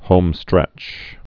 (hōmstrĕch)